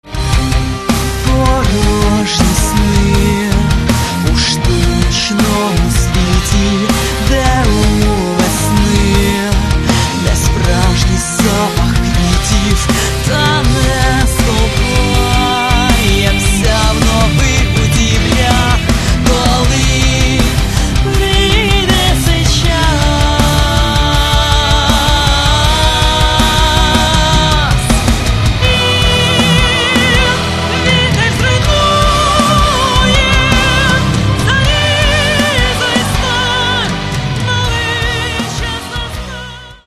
Каталог -> Рок та альтернатива -> Енергійний рок
Альтернативна музика?
Мелодійний хеві-метал?